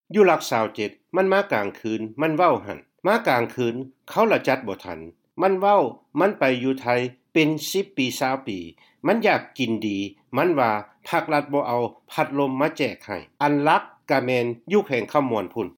ພະນັກງານຜູ້ອະວຸດໂສເຂດນະຄອນຫລວງວຽງຈັນ ກ່າວເຖິງພວກແຮງງານລາວ ທີ່ກັບຄືນມາຈາກໄທ